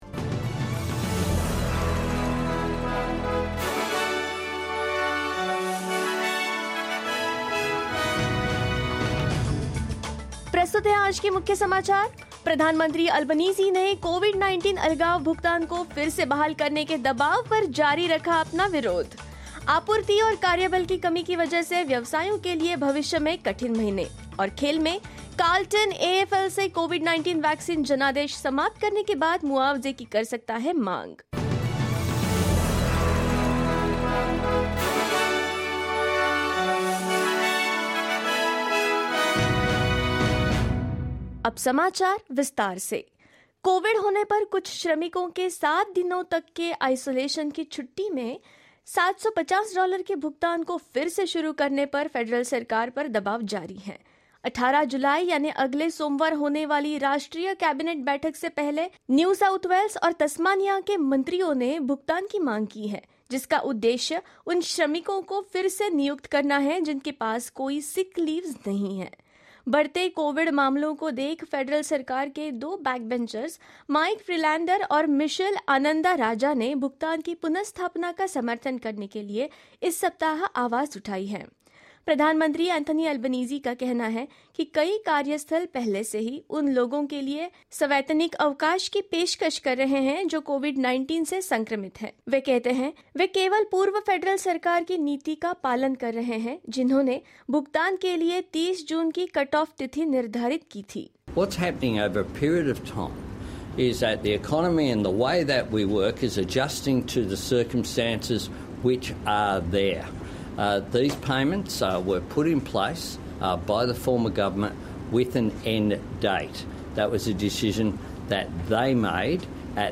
hindi_news_1507.mp3